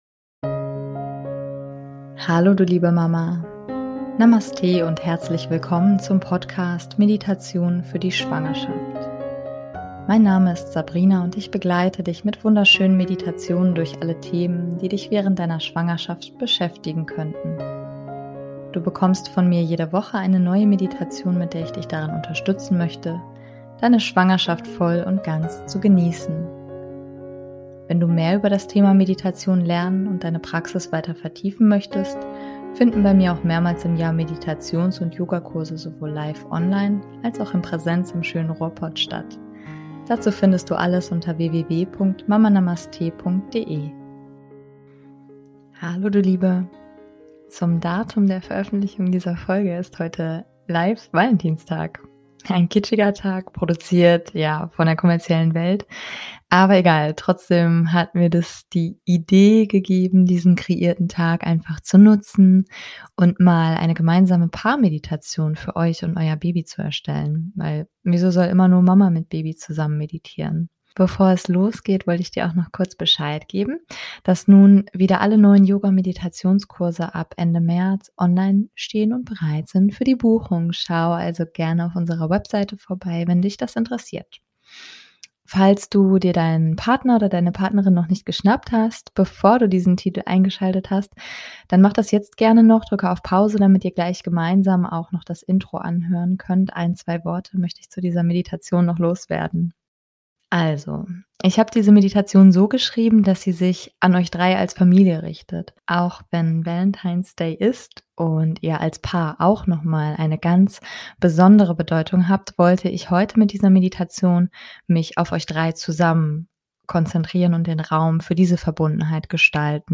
#075 - Paar Meditation - Gemeinsame Verbundenheit mit dem Baby ~ Meditationen für die Schwangerschaft und Geburt - mama.namaste Podcast